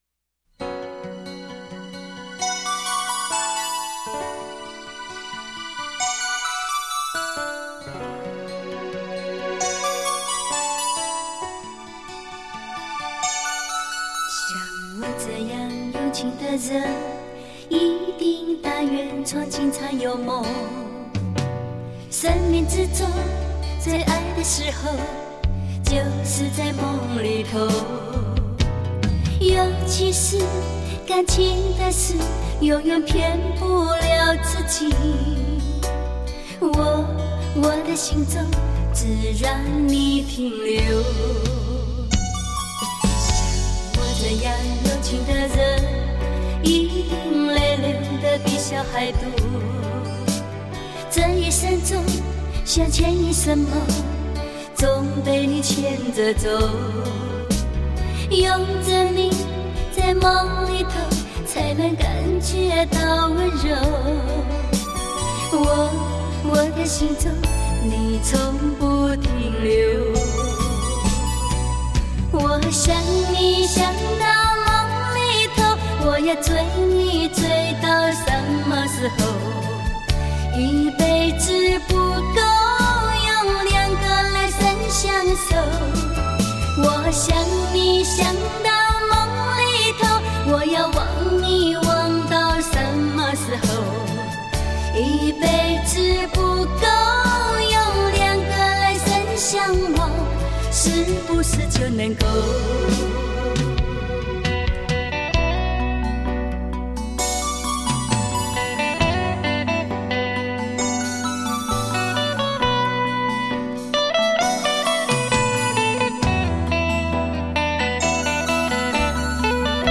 纯熟的演唱